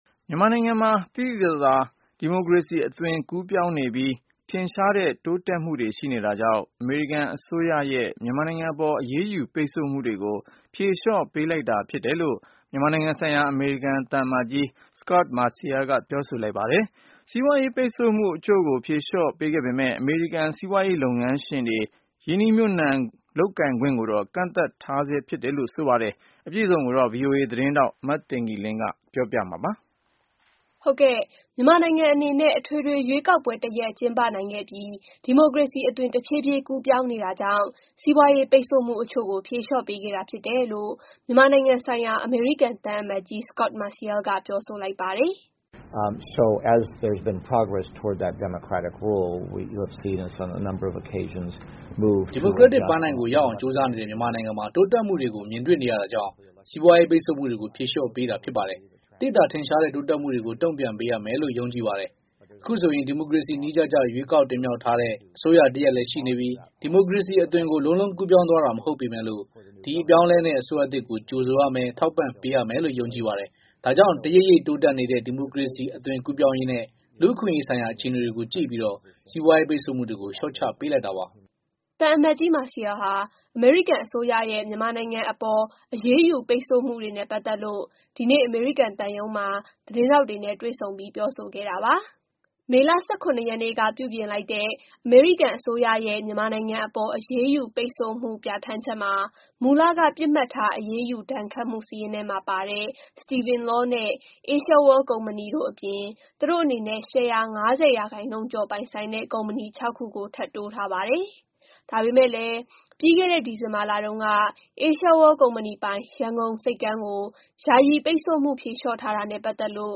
သံအမတ်ကြီး Marcial ဟာ အမေရိကန်အစိုးရရဲ့ မြန်မာနိုင်ငံအပေါ် အရေးယူ ပိတ်ဆို့မှုတွေနဲ့ ပတ်သက်လို့ ဒီနေ့ အမေရိကန်သံရုံးမှာ သတင်းထောက်တွေ နဲ့ တွေ့ဆုံပြီး ပြောဆိုခဲ့တာပါ။